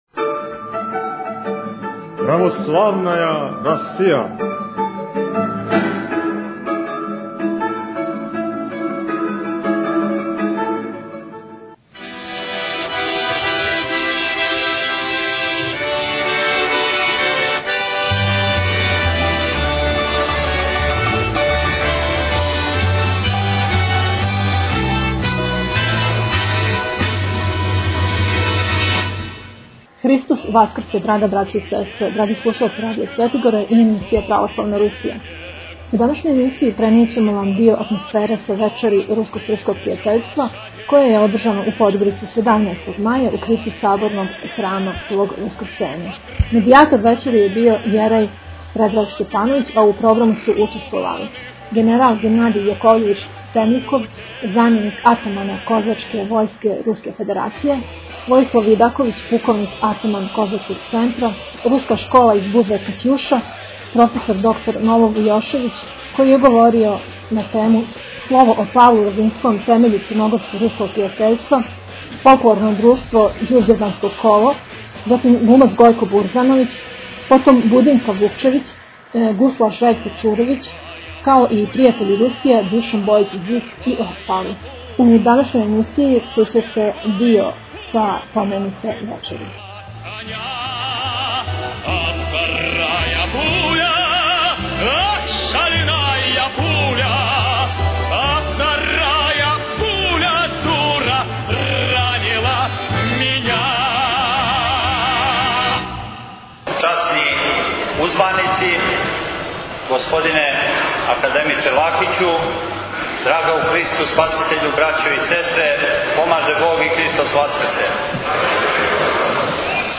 Аудио :: Browse by :: title Audio by title pravoslavna_rusija_124 Православна Русија CXXIV Tagged: Православна Русија 31:14 минута (4.48 МБ) 17. маја у крипти саборног храма Христовог васкрсења у Подгорици је одржано вече руско - српског пријатељства. Дио атмосфере са те вечери чућете у овој емисији.